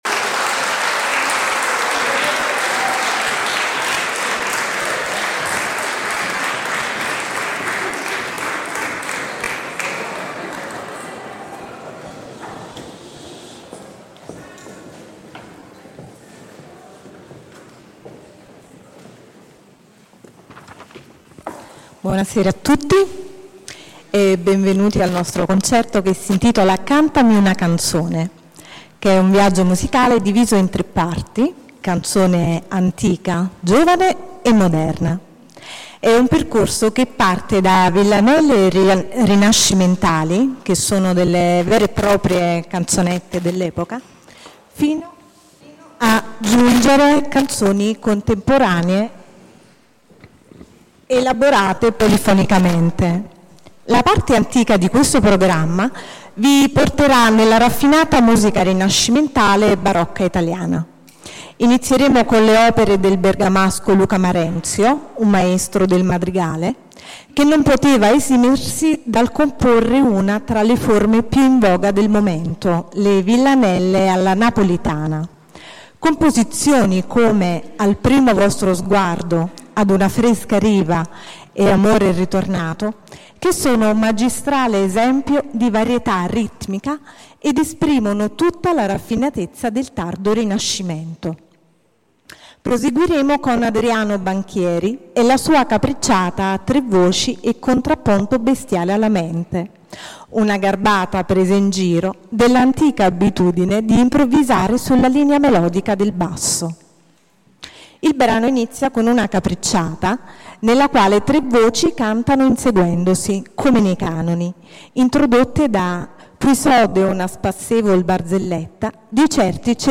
Il 7 giugno, presso la sala parrocchiale “Don Benito Ricciardiello”, il coro universitario “Joseph Grima” si è esibito nel concerto “Cantami una canzone”.
Concerto “Cantami una canzone” del 7 giugno 2024 – Coro Universitario “Joseph Grima”
Registrazione del concerto "Cantami una canzone" del 7 giugno 2024 audio-and-video-player require JavaScript